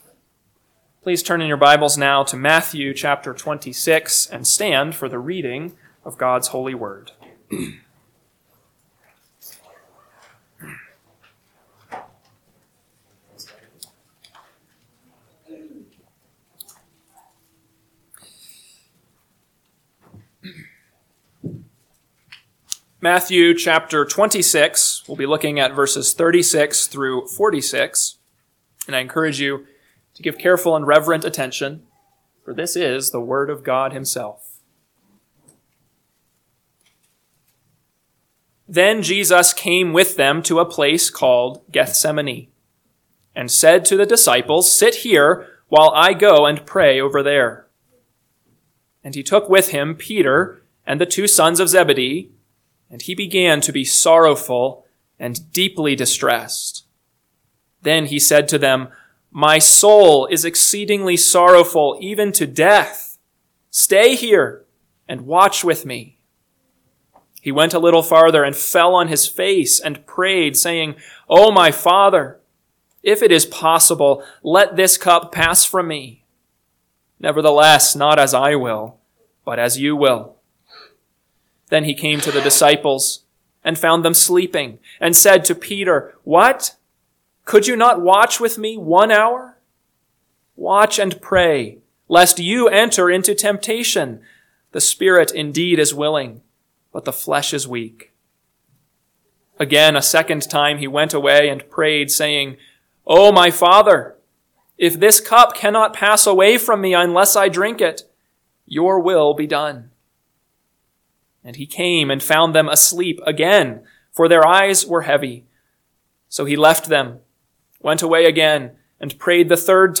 AM Sermon – 3/9/2025 – Matthew 26:36-46 – Northwoods Sermons